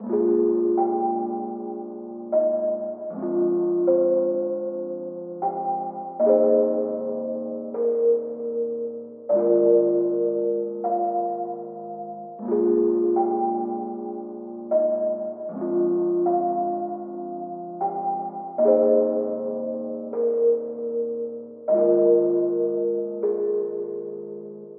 黑暗中的黄昏环路
描述：在Omnisphere 2中使用Grandma's Piano Memories补丁，使用FL Studio 12制作。
Tag: 155 bpm Chill Out Loops Rhodes Piano Loops 4.17 MB wav Key : D